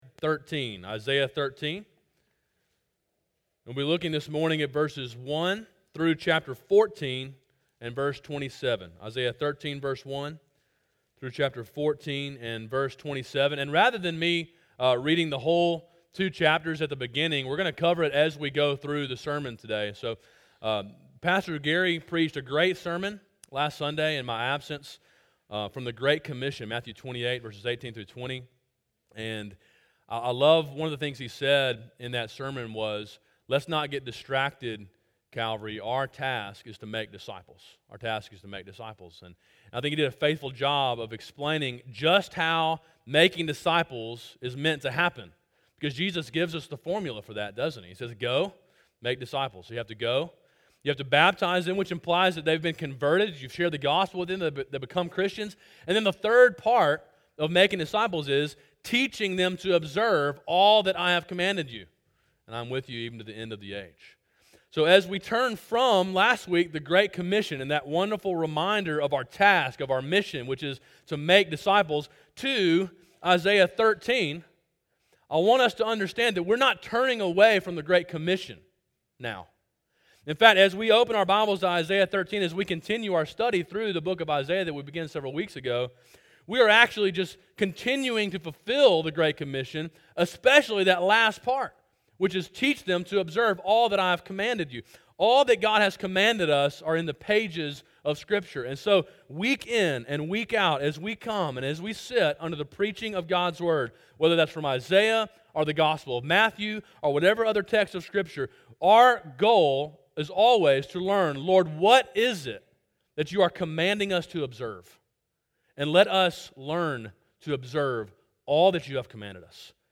A sermon in a series on the book of Isaiah.